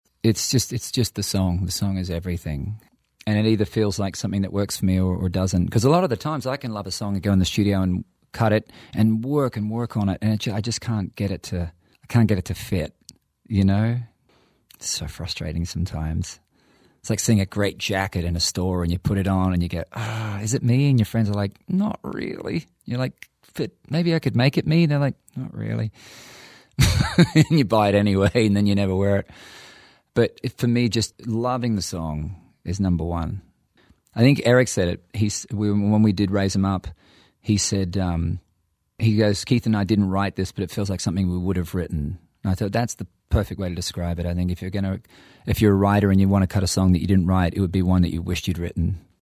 Audio / Keith Urban talks about recording songs that he didn't write.